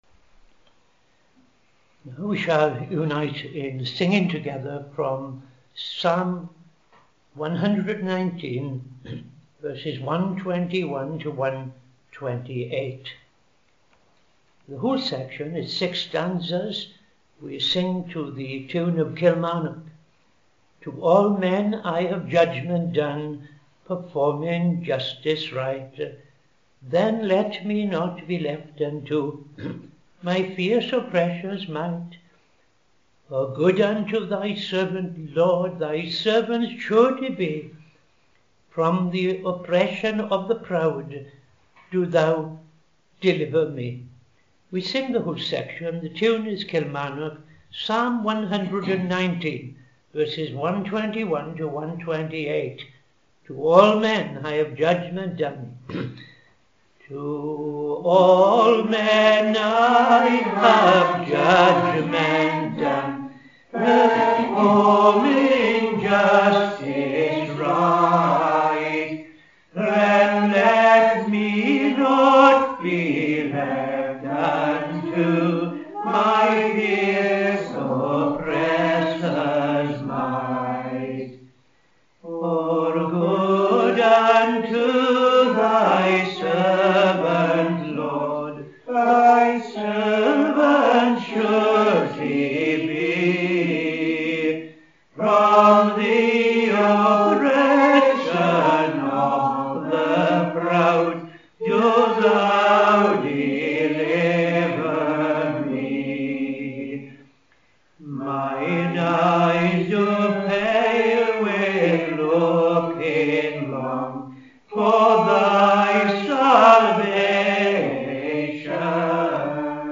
Evening Service - TFCChurch
5.00 pm Evening Service Opening Prayer and O.T. Reading I Chronicles 4:21-43